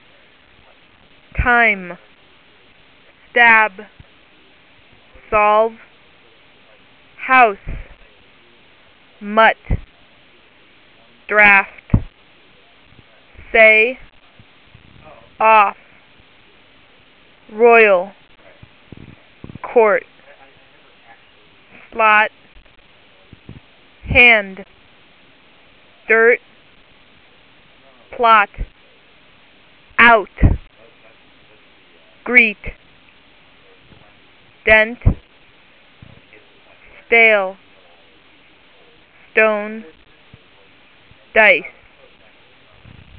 Again, 20 words will be read with an interval of about one second between each word.